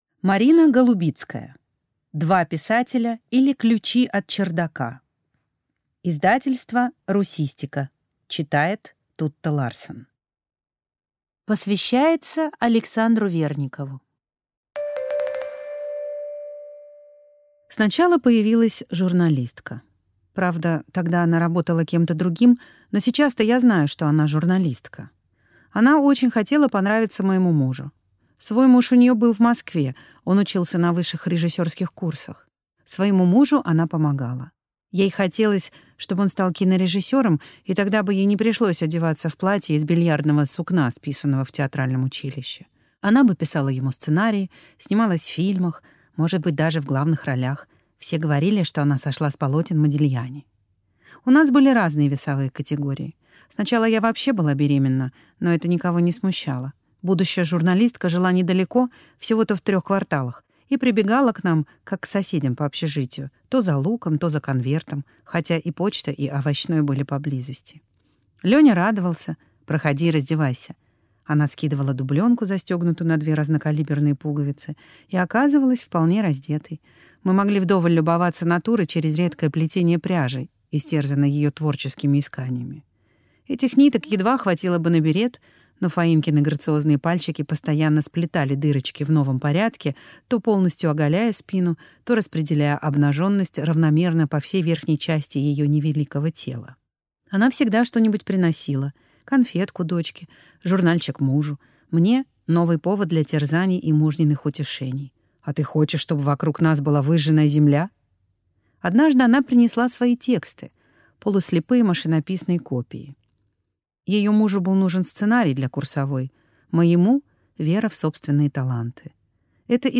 Aудиокнига Два писателя, или Ключи от чердака Автор Марина Голубицкая Читает аудиокнигу Тутта Ларсен.